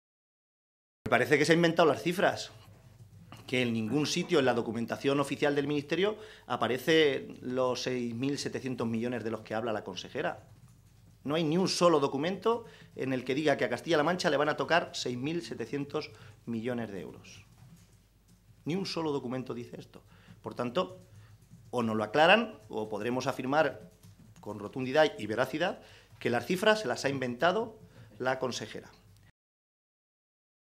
En la misma rueda de prensa, y a preguntas de los medios, el diputado regional del PSOE se ha referido a la conferencia sectorial de Agricultura que, ayer, decidía el reparto en España de los fondos de la Política Agraria Común (PAC).